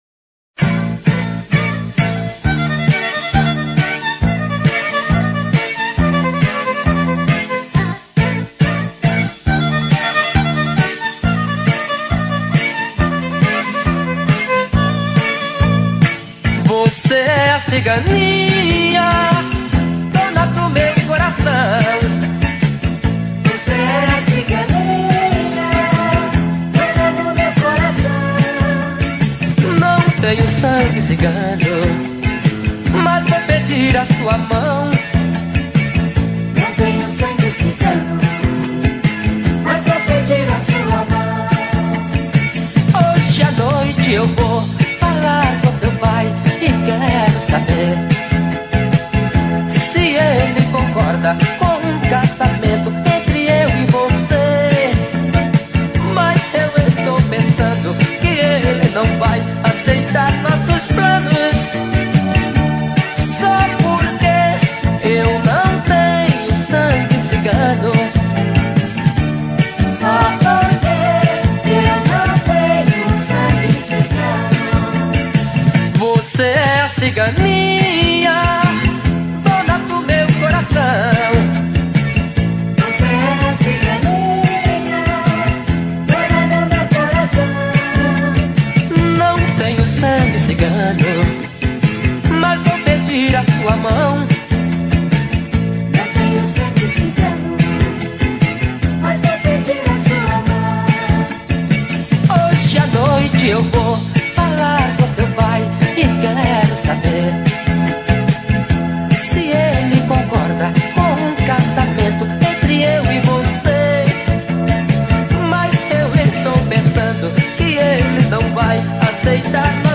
Bregas